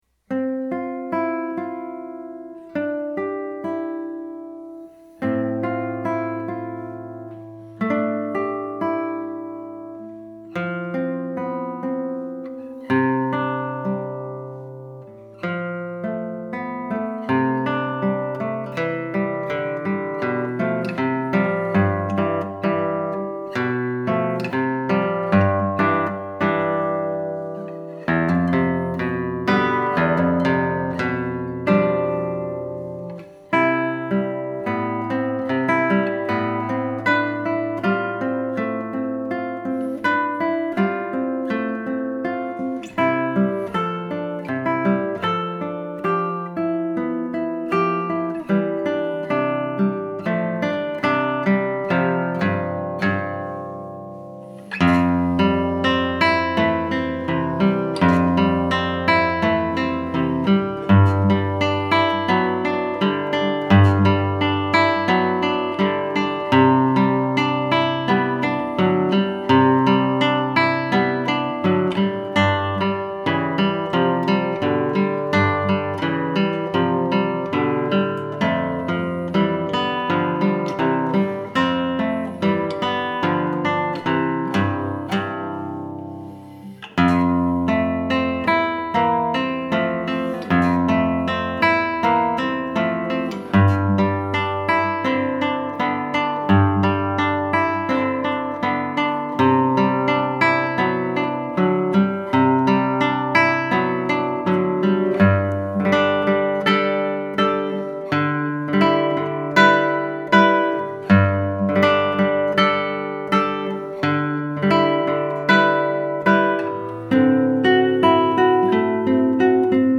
ALT-CLASSICAL
Guitar